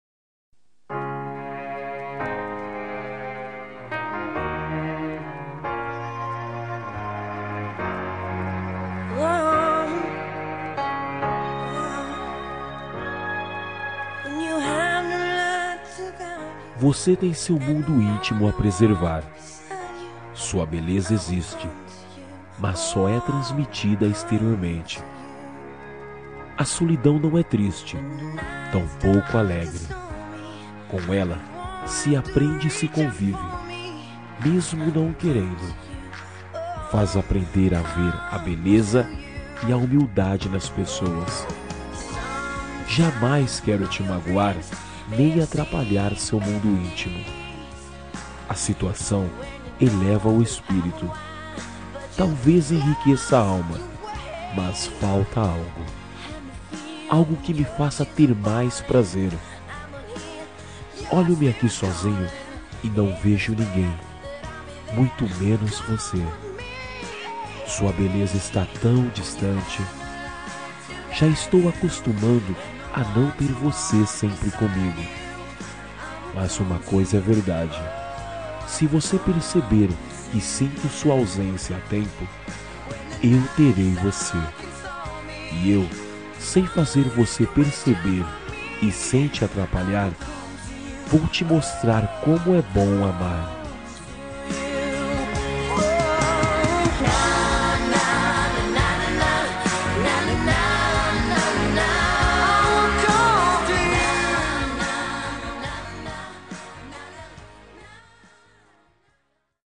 Toque para Não Terminar – Voz Masculina – Cód: 500 Me dê Atenção
500-me-de-atencao-masc.m4a